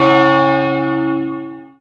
• 声道 單聲道 (1ch)